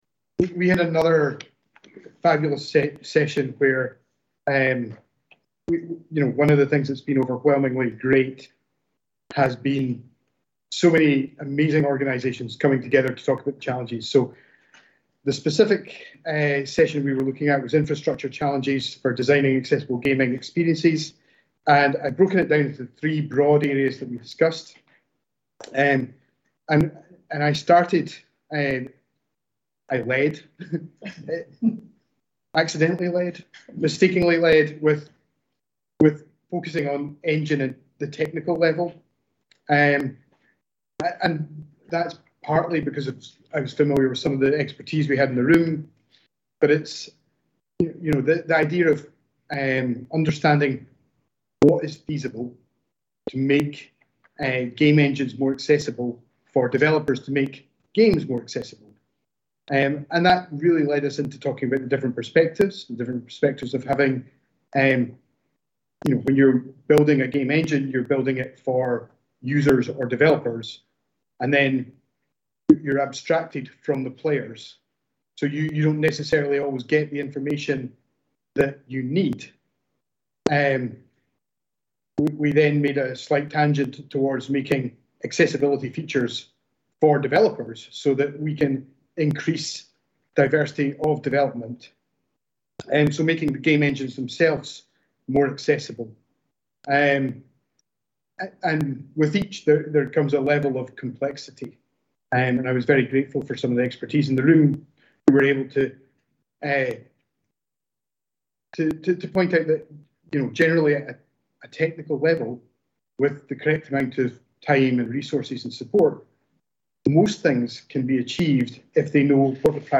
Accessible Gaming Symposium - Breakout Session 5 Presentation - Interface Challenges for Designing Accessible Gaming Experiences